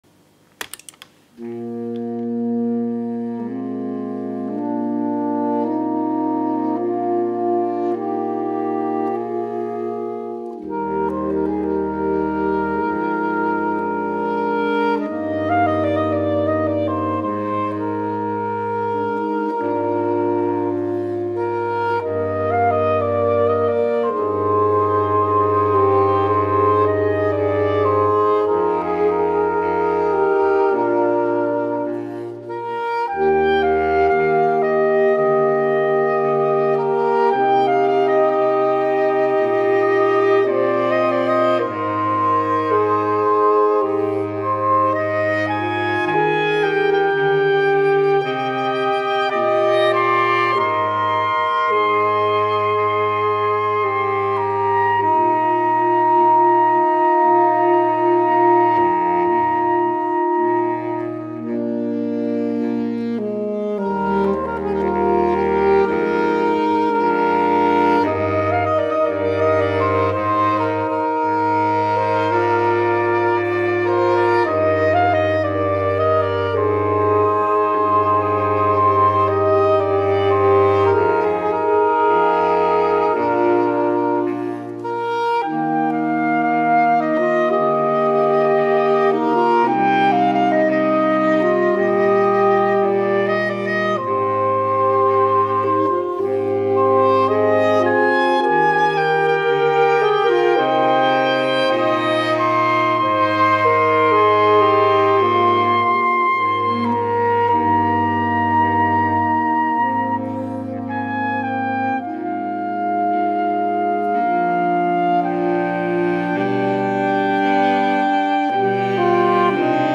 Siena Saxophone Quartet are a fresh, new slick group with a unique saxophone sound that ranges from performing classical music to modern day songs.
So if you're looking for something different with a funky edge to something more traditional, Siena Saxophone Quartet are the band for you.
The four ladies, when not performing together as Siena Saxophone Quartet, can be found touring with West End Musicals and alongside The National Symphony Orchestra and The Royal Philharmonic Orchestra.